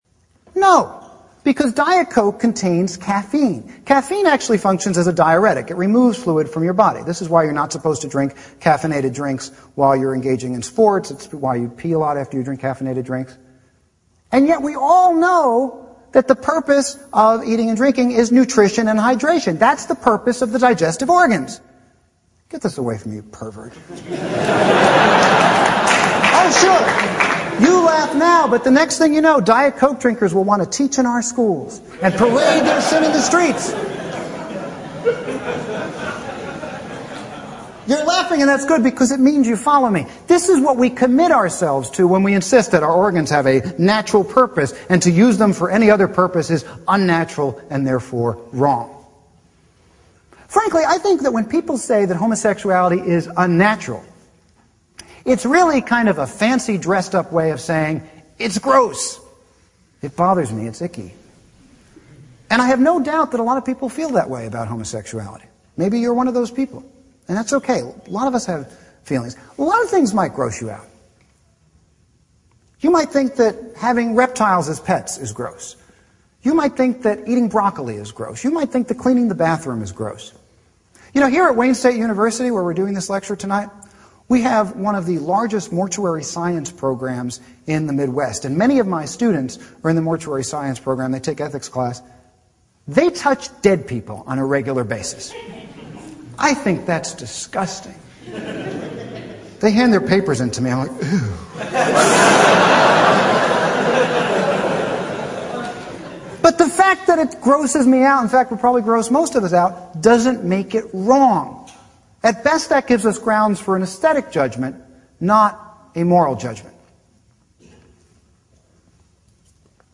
欧美名人演讲 第71期:同性恋与道德的关系(17) 听力文件下载—在线英语听力室